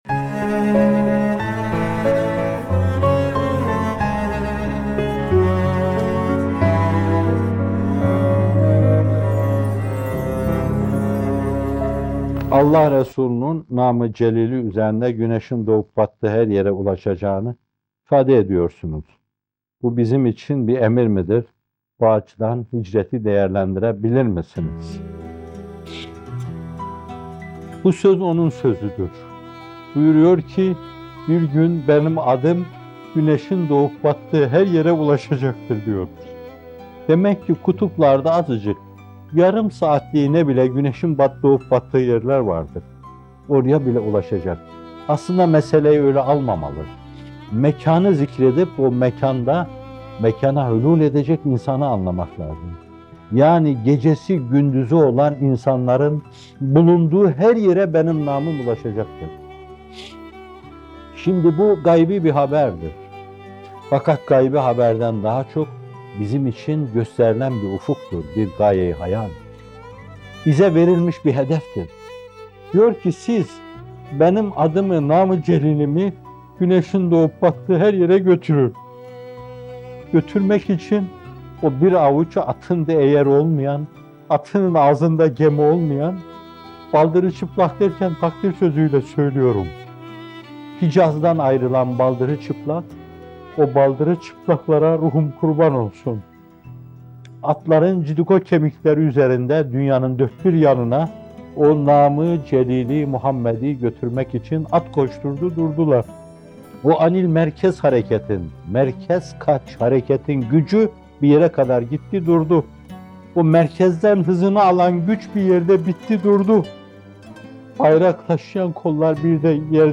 Nâm-ı Celîl-i Muhammedî (Sallallâhu Aleyhi ve Sellem) - Fethullah Gülen Hocaefendi'nin Sohbetleri